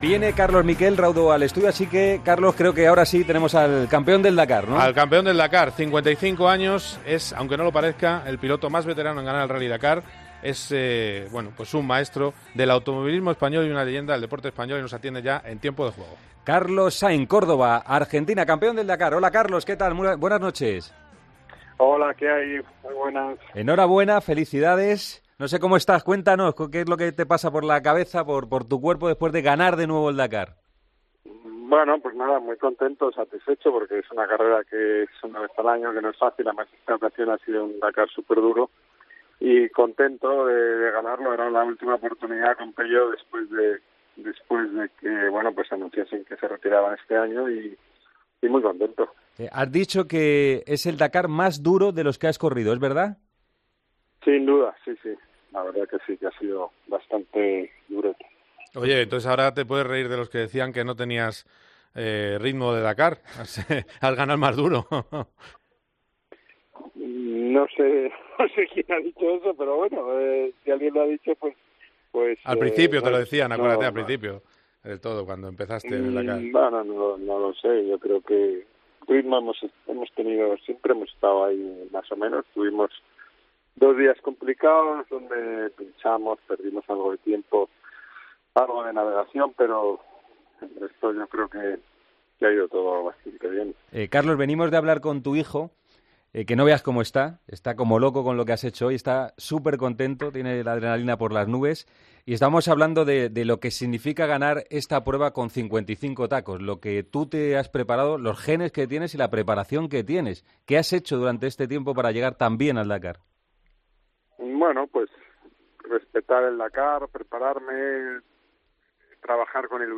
Entrevista en Tiempo de Juego
Con Paco González, Manolo Lama y Juanma Castaño